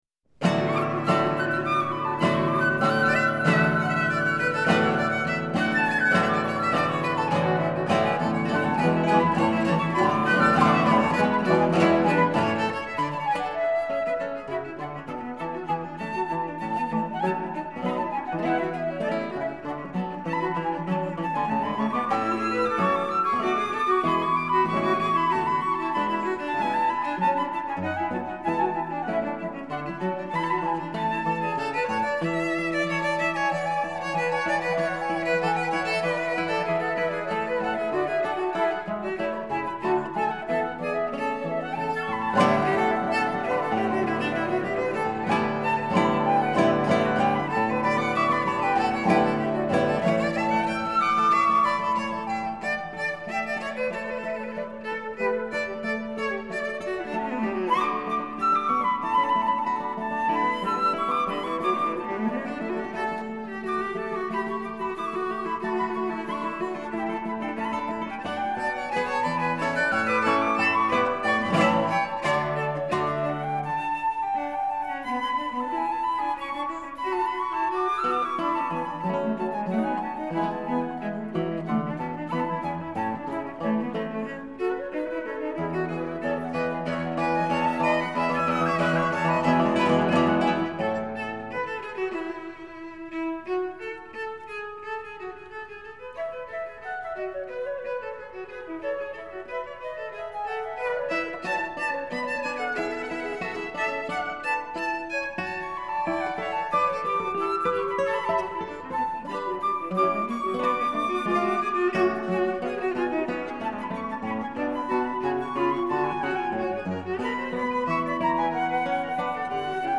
SCORING:  Flute, viola, guitar